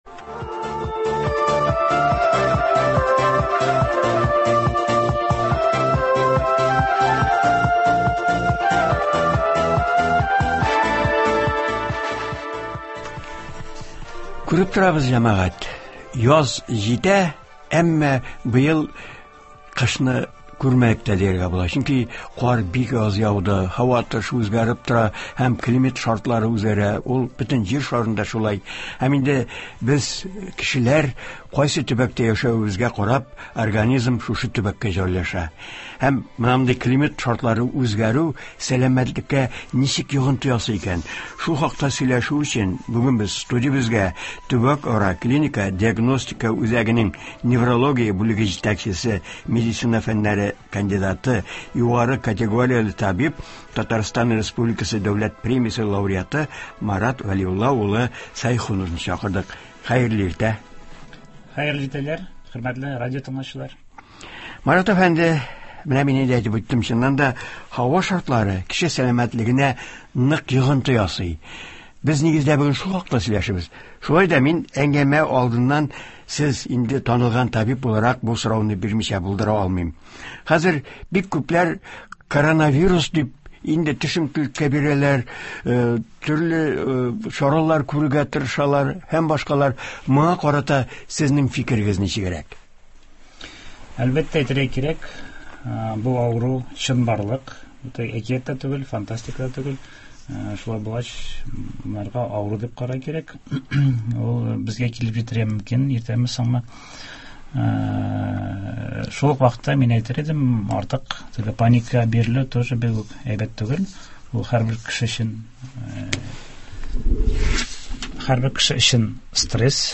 туры эфирда сәламәтлекне саклау чаралары турында сөйләячәк һәм тыңлаучылар сорауларына җавап бирәчәк